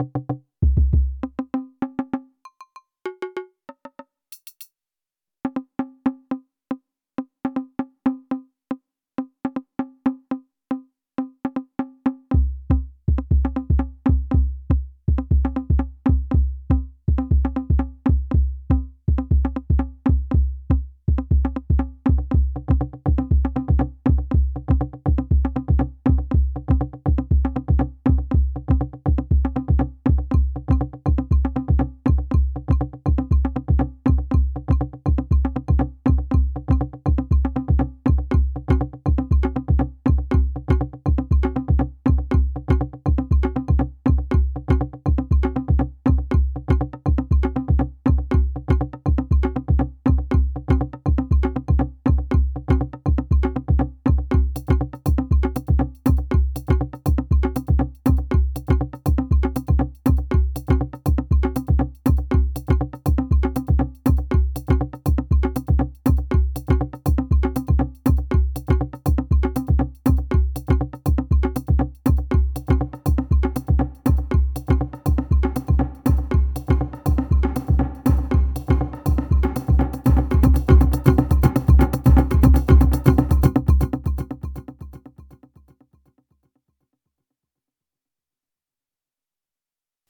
1 x Pattern, 8 x SY Toy, unmuted one track after another, then drove everything into reverb and delay using control all - it’s a bit of a muddy mess but I decided to toss it out anyway ;p
The above snippet had been recorded in mono accidentally, so here’s a stereo recording: